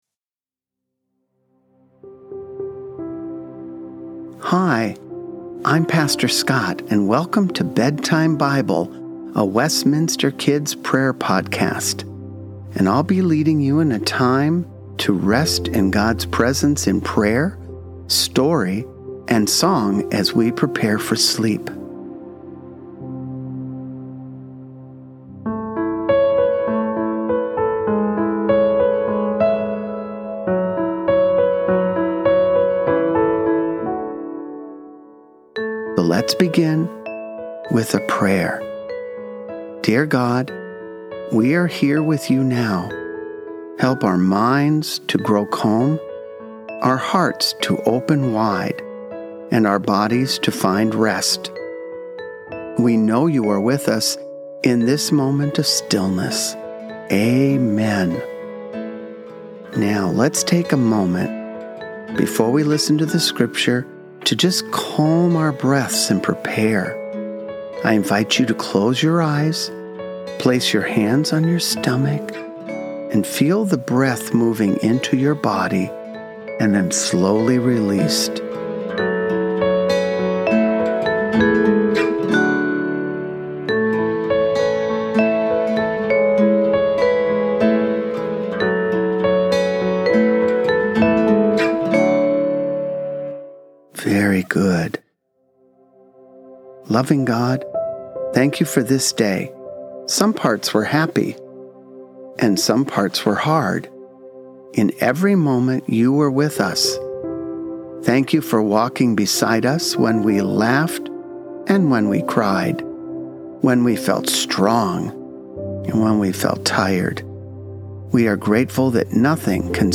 Evening Song - "All Through the Night" - words by Sir Harold Boulton and the music is a traditional Welsh folk tune.
Each podcast will feature calming music, Scripture and prayers to help children unwind from their day.